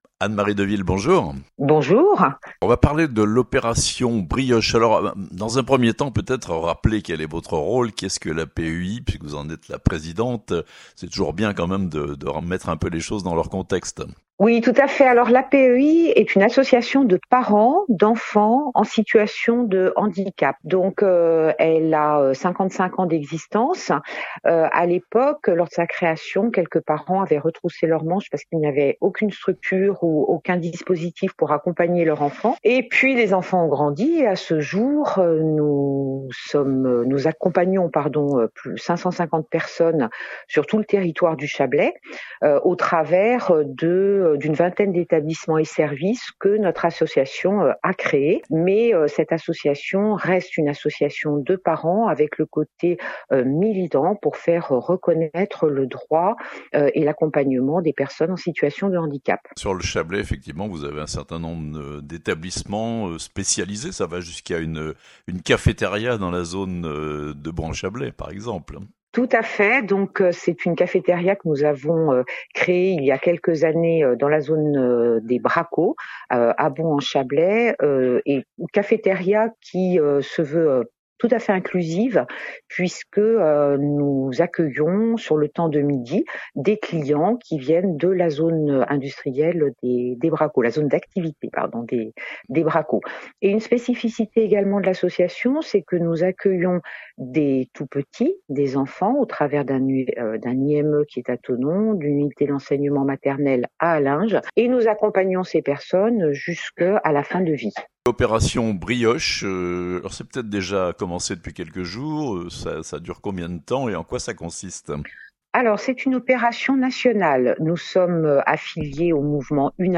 L'APEI du Chablais participe à "l'Opération Brioches" nationale (interview)